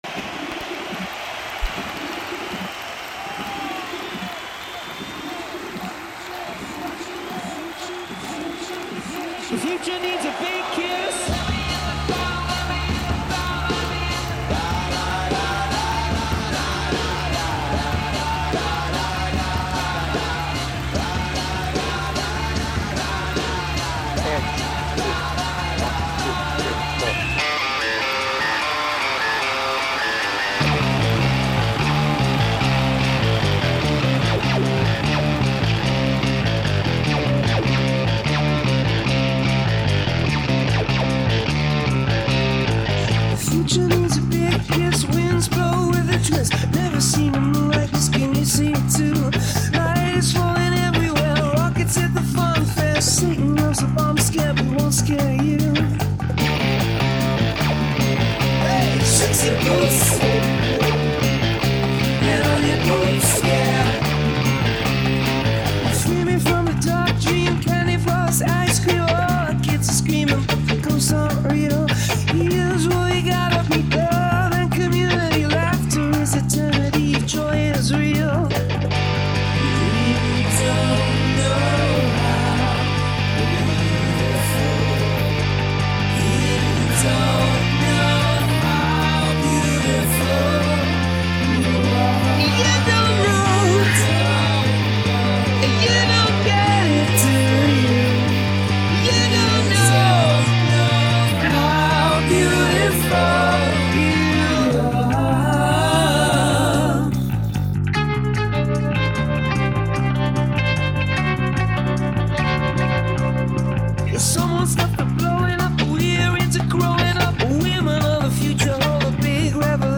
With vocals
Based on 360° Tour and album